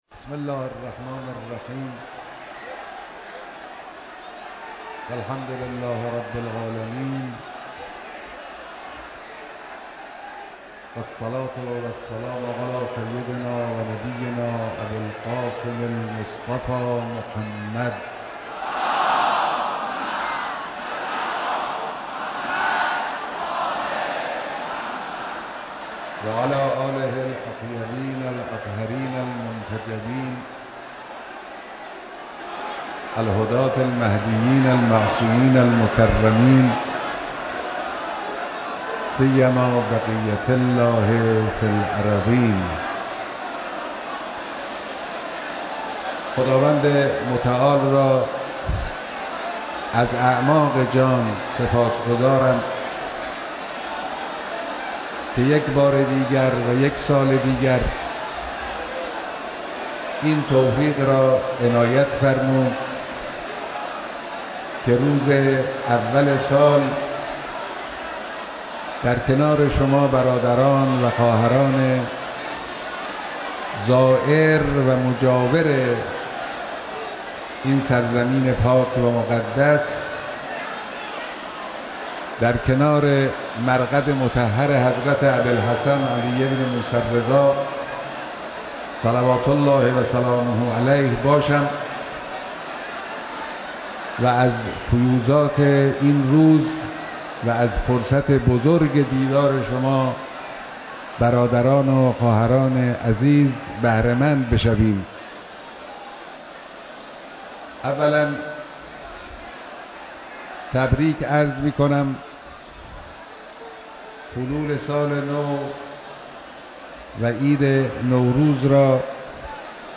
دیدار اجتماع عظیم زائران حرم رضوی و مردم مشهد